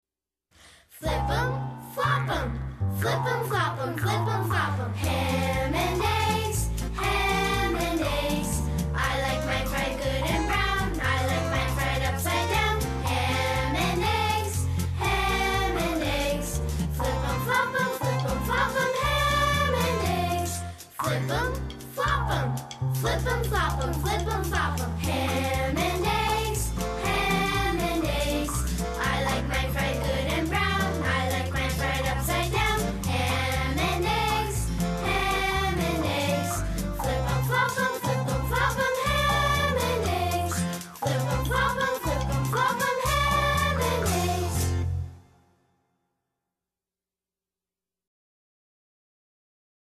英语童谣
以生动活泼的情境式故事，搭配朗朗上口的歌曲，激发幼儿的学习潜能及培养古典、艺术气质。